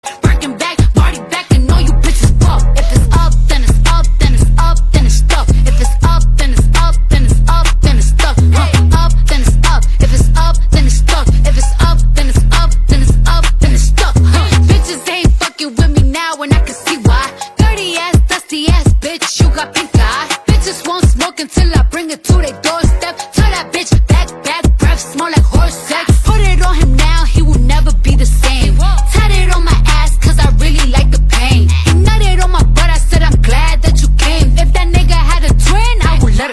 Kategorien POP